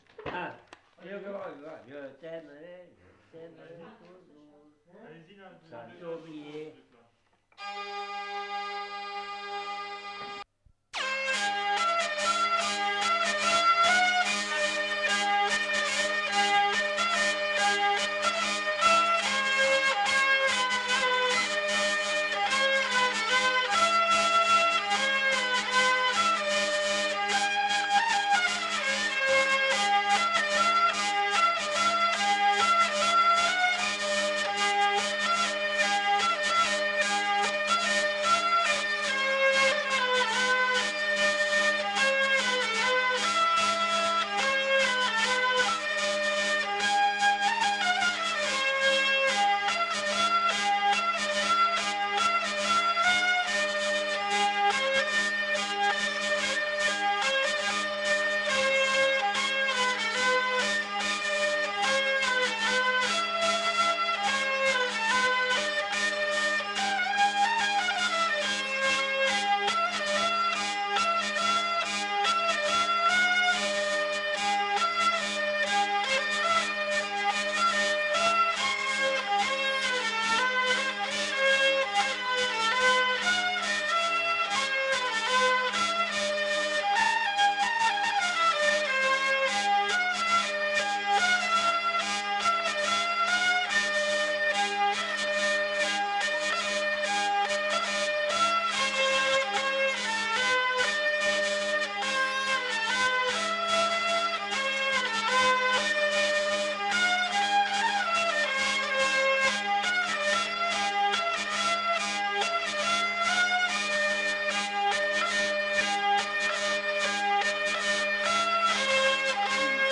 Lieu : Vielle-Soubiran
Genre : morceau instrumental
Instrument de musique : vielle à roue
Danse : varsovienne
Notes consultables : En début de séquence quelques paroles sont chantées.